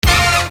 Hit 005.wav